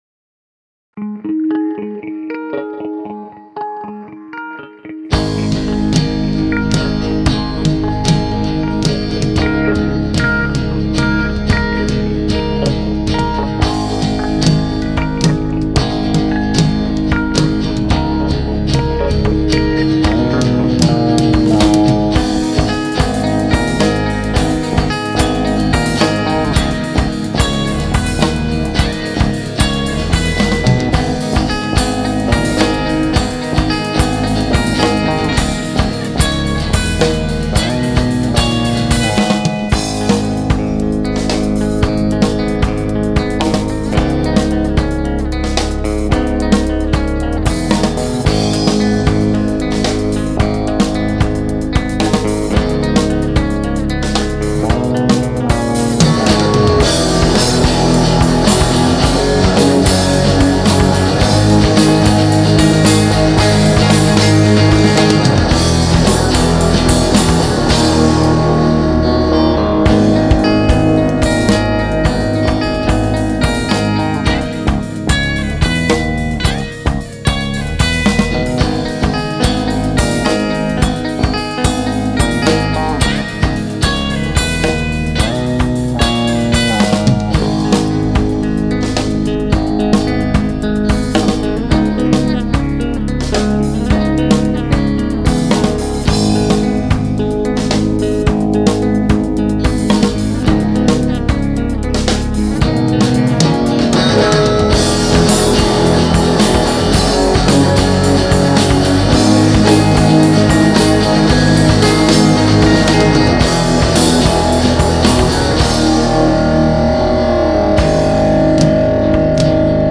Mushmellow_Christmas_minus.mp3